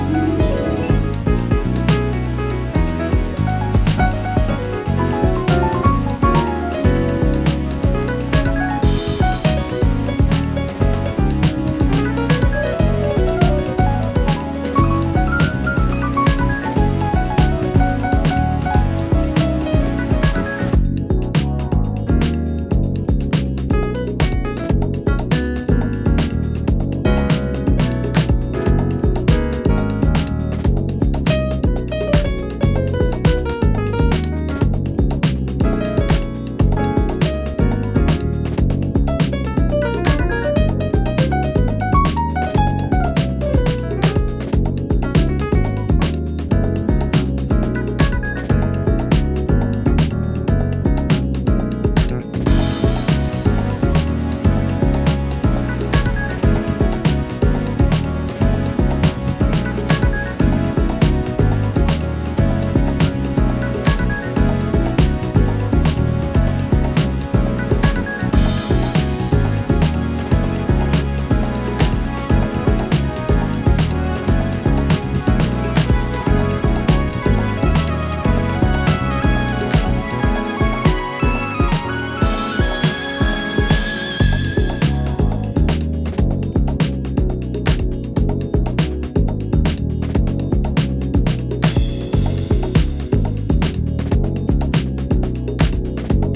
ジャンル(スタイル) JAZZ HOUSE / HOUSE